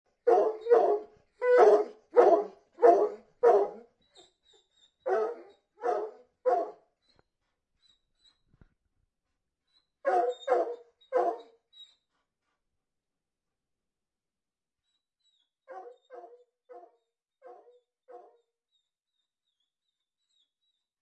041178_dog Barking 1.mp3 Sound Button - Free Download & Play